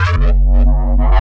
Bass 1 Shots (101).wav